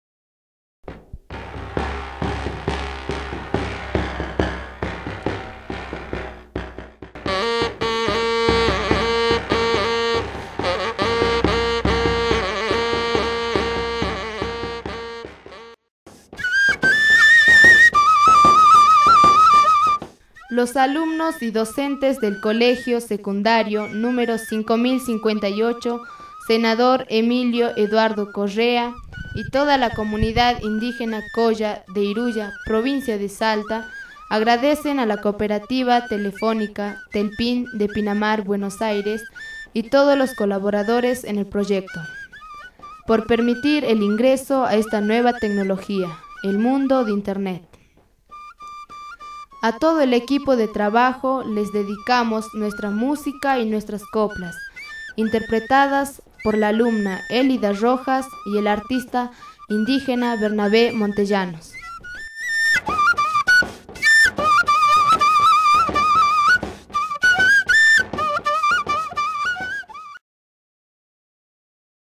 ARCHIVOS DE SONIDO "COPLAS" :